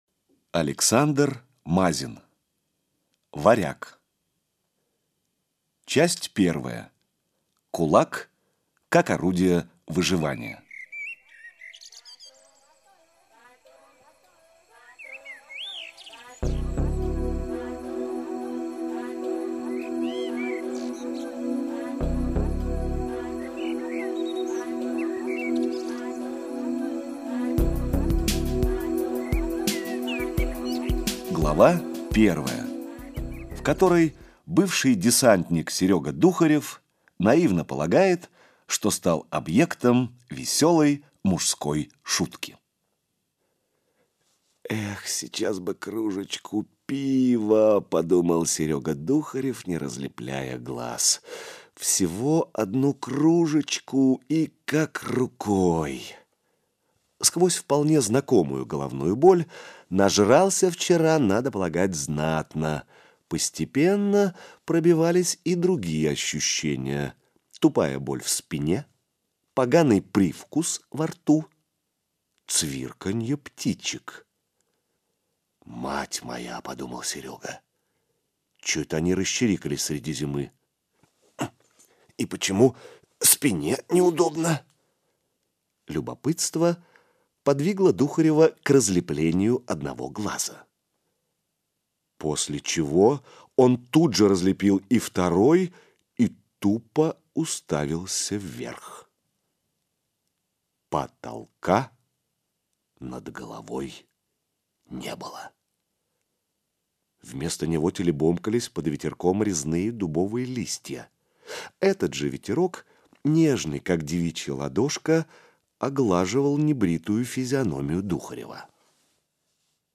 Аудиокнига Варяг - купить, скачать и слушать онлайн | КнигоПоиск